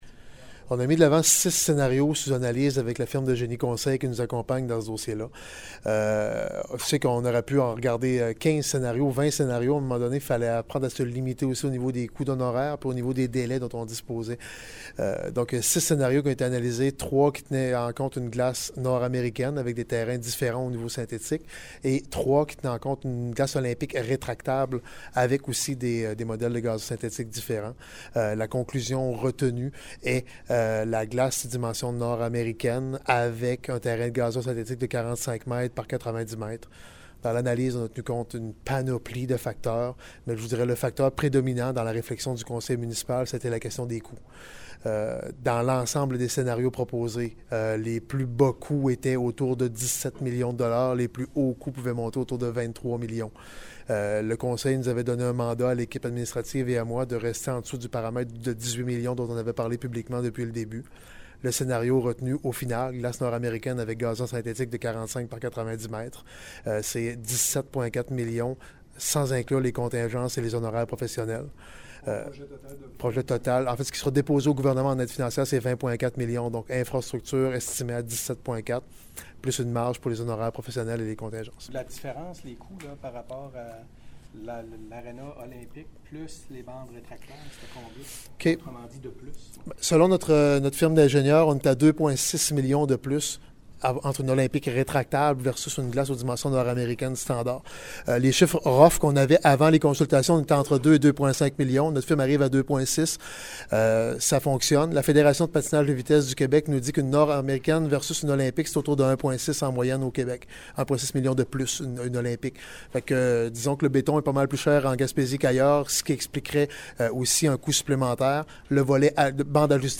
Point de presse du maire de Gaspé, Daniel Côté: